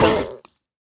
Minecraft Version Minecraft Version snapshot Latest Release | Latest Snapshot snapshot / assets / minecraft / sounds / mob / turtle / hurt1.ogg Compare With Compare With Latest Release | Latest Snapshot
hurt1.ogg